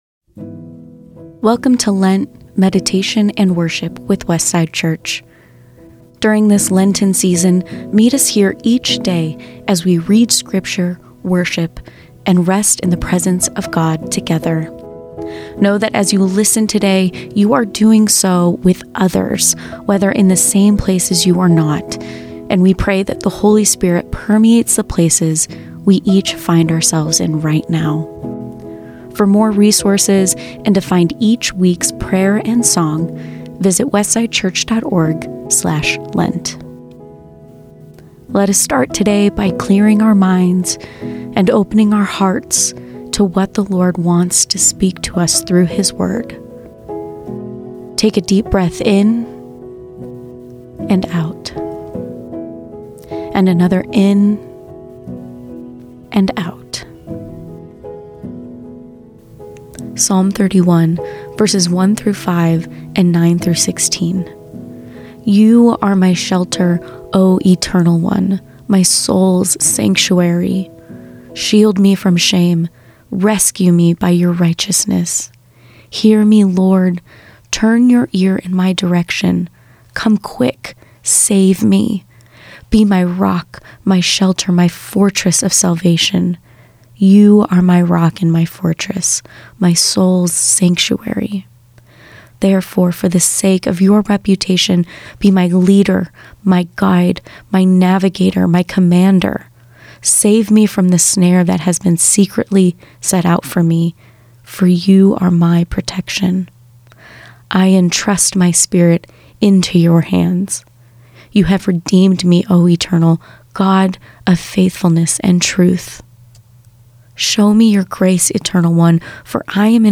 A reading from Psalm 31:9-16